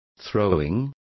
Complete with pronunciation of the translation of throwings.